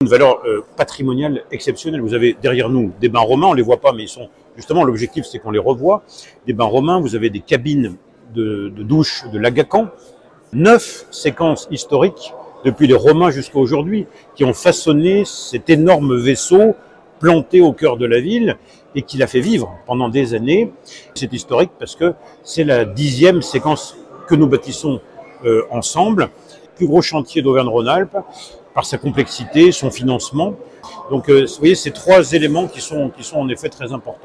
Un chantier pour le moins historique comme l’explique Renaud Beretti le maire d’Aix-les-Bains :